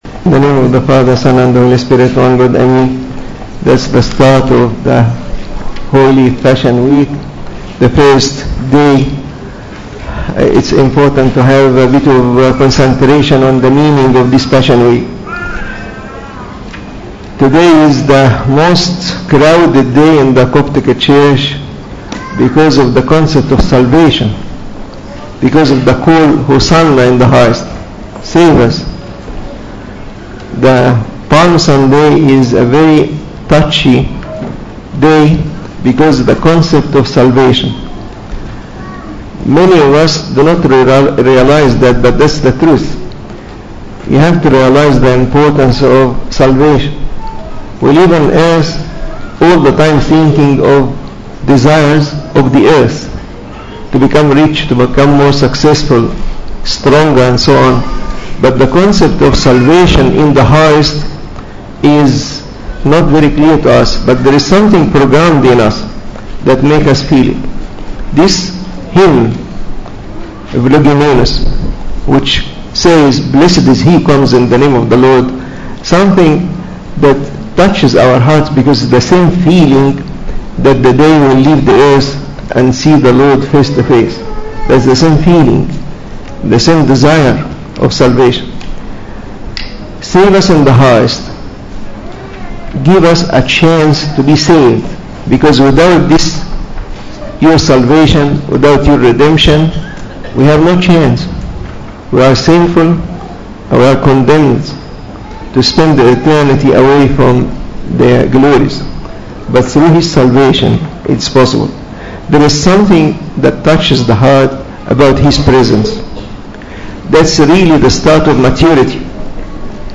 Service Type: English Pascha Week Sermons